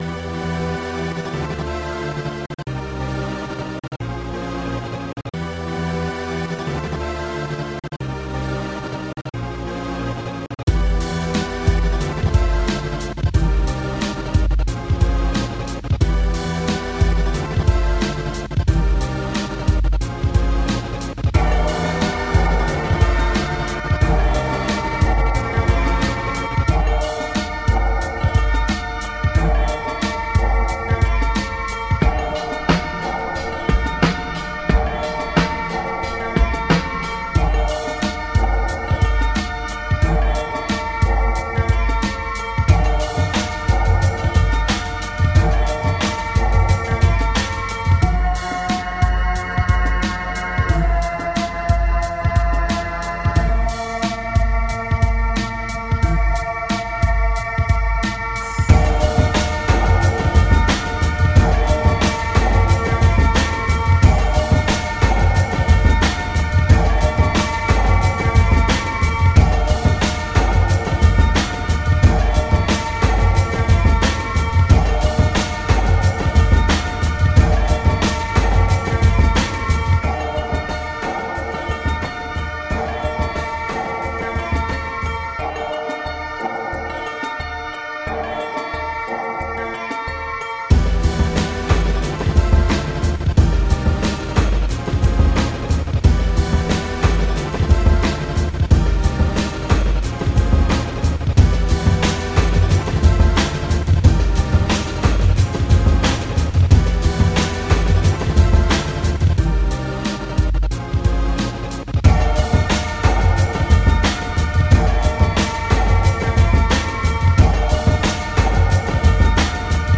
Clean, elemental electronic.
Tagged as: Electronica, Pop, Experimental Electronic